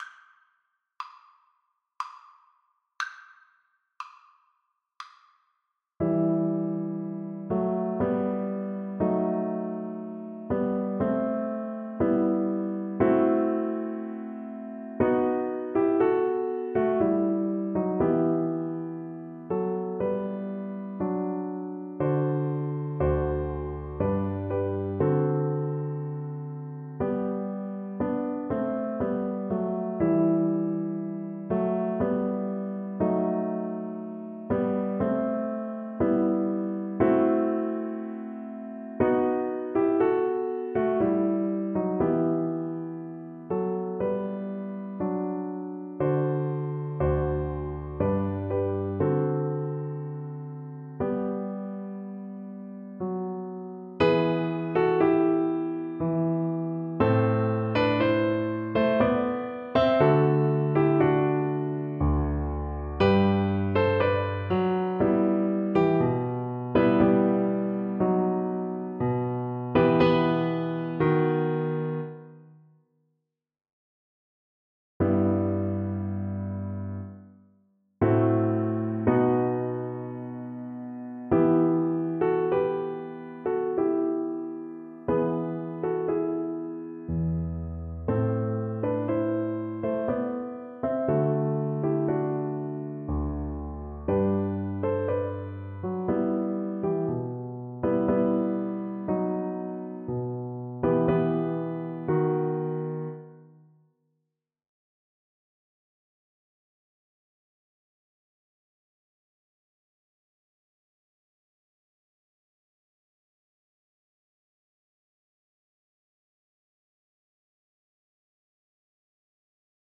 3/4 (View more 3/4 Music)
Largo con espressione =60
Classical (View more Classical Viola Music)